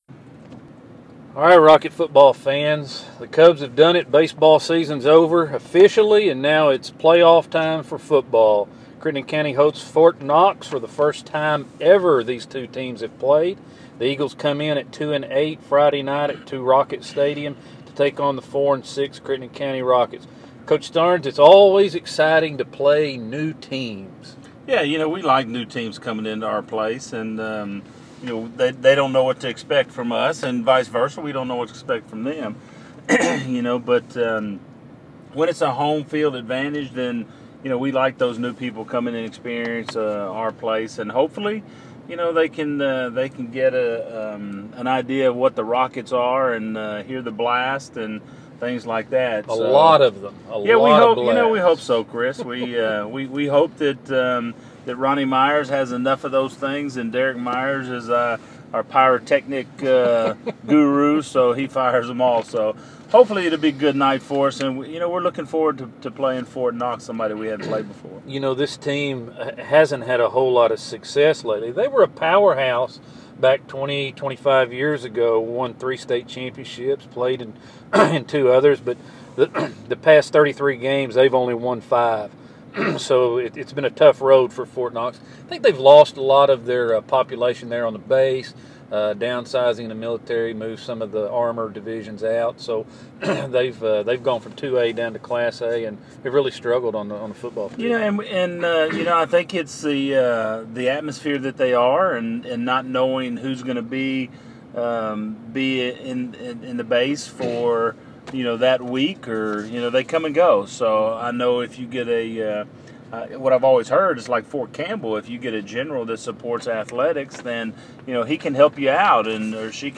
Rocket Pre-Game Interview Class A Playoffs - Round One Crittenden vs Fort Campbell 7pm Friday, Rocket Stadium